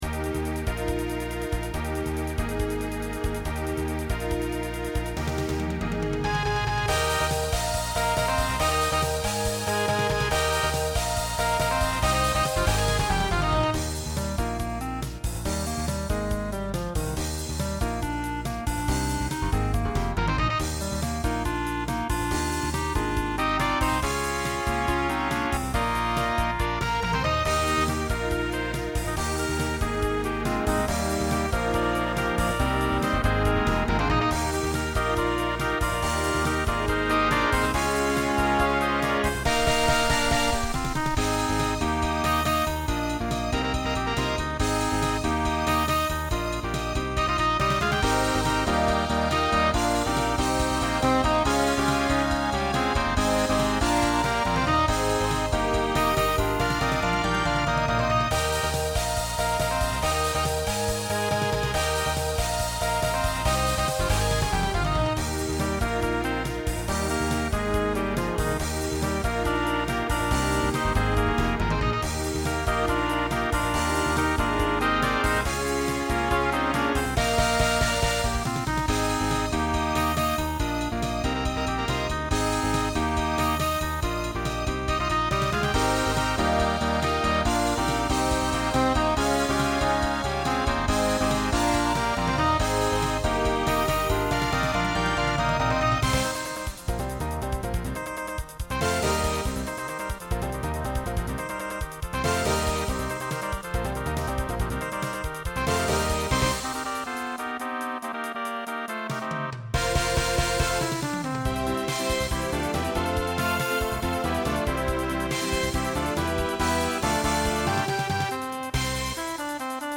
Voicing SATB Instrumental combo Genre Pop/Dance , Rock